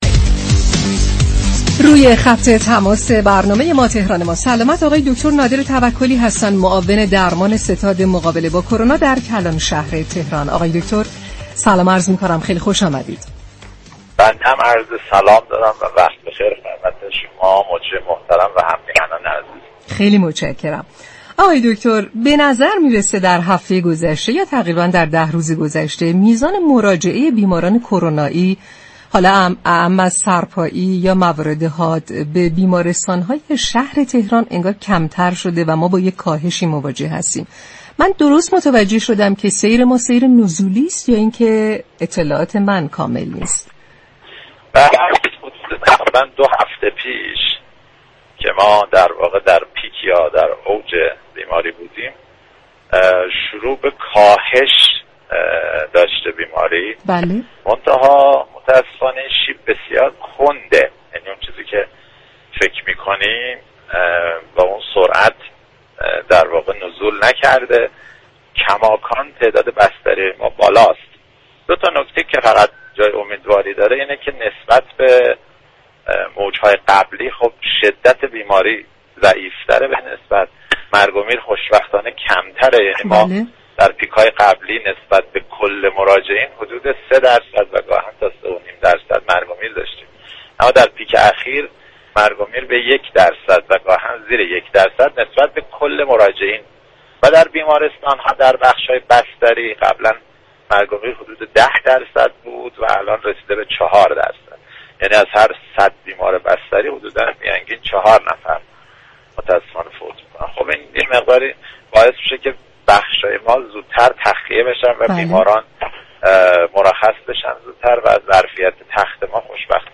در گفتگو با برنامه «تهران ما سلامت»